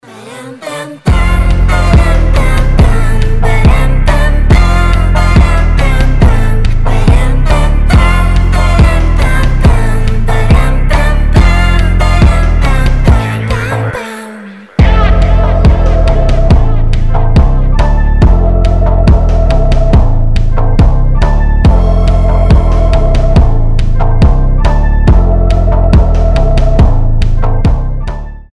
• Качество: 320, Stereo
атмосферные
женский голос
alternative
Атмосферная альтернатива